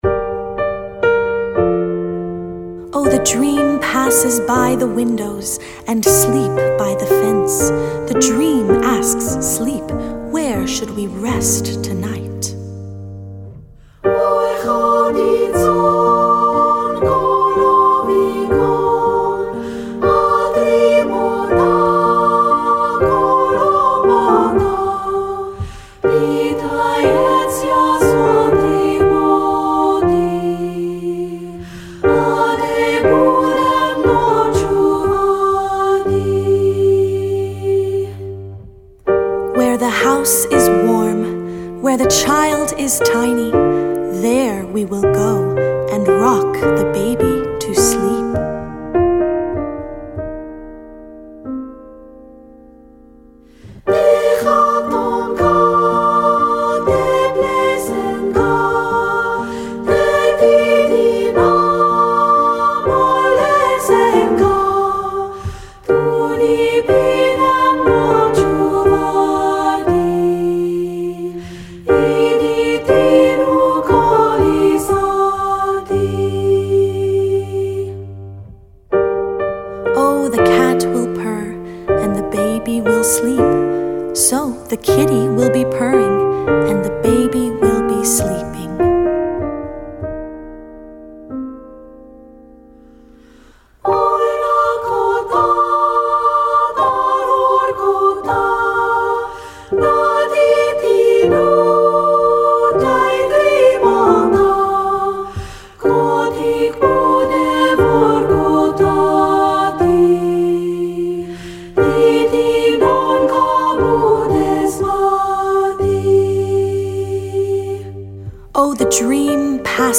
Ukrainian Folk Song